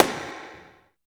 51 SNARE 5-L.wav